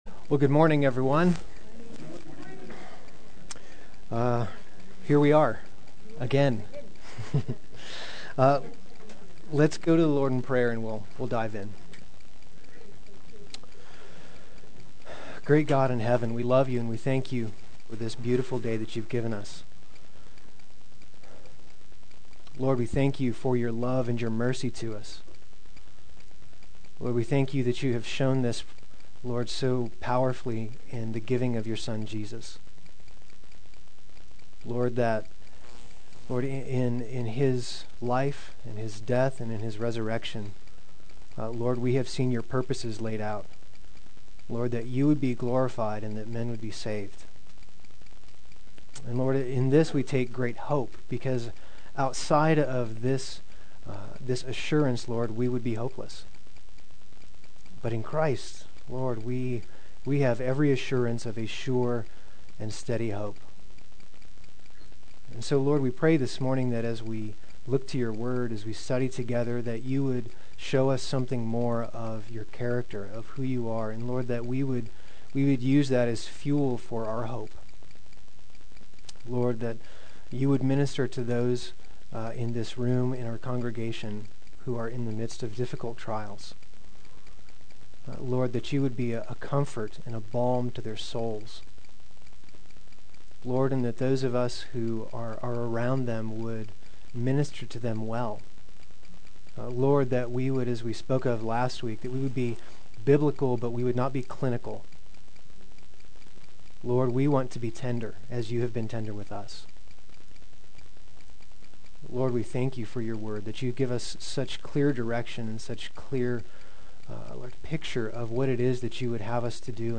Play Sermon Get HCF Teaching Automatically.
Trials Understood Adult Sunday School